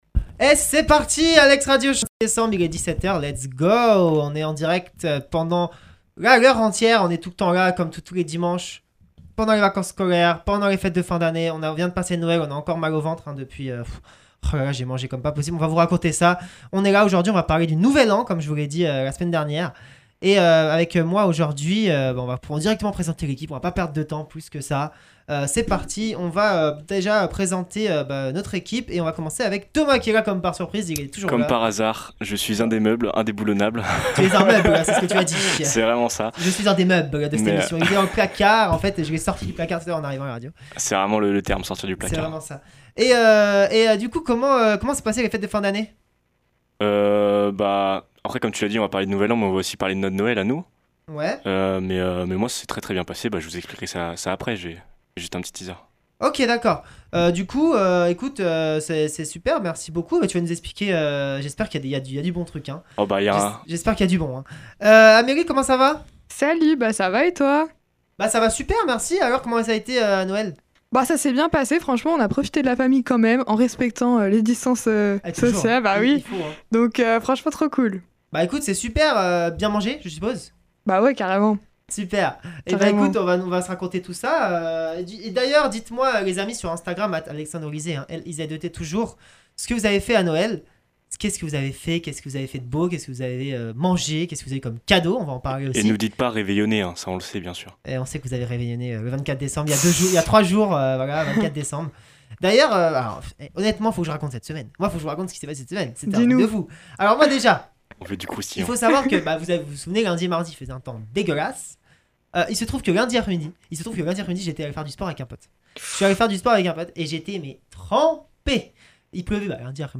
Des musiques viendront rythmer le show toutes les 8 à 10 minutes et nous retrouverons la météo en début d’émission.